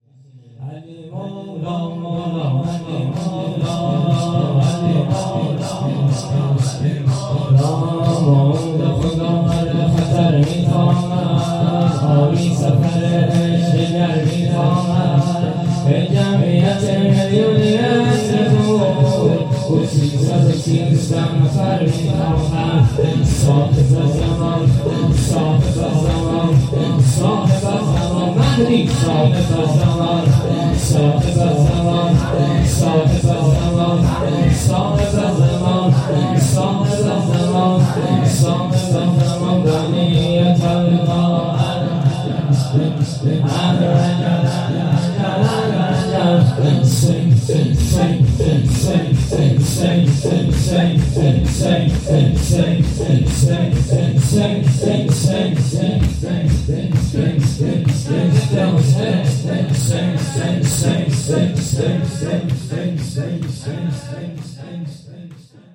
جلسه هفتگی|۱۶شهریور۱۴۰۰|شب آخر ماه محرم